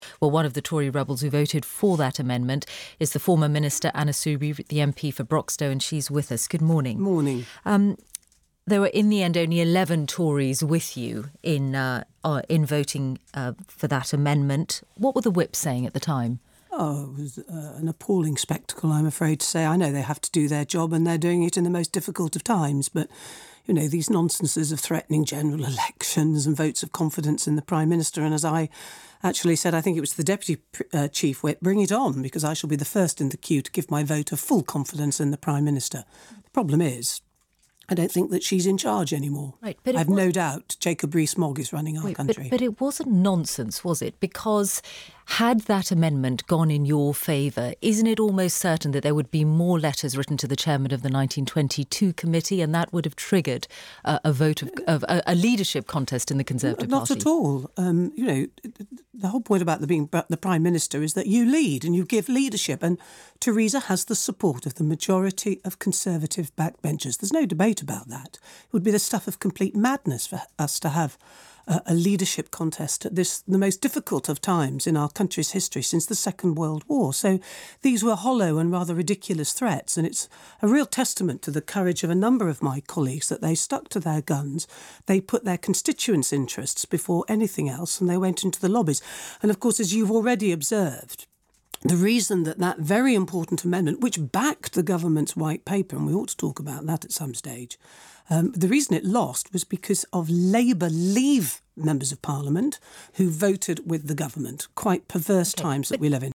Anna Soubry, MP for Broxtowe, made the claim on the BBC Radio 4 Today programme on 18 July. She was discussing the 17 July parliamentary vote on the customs bill.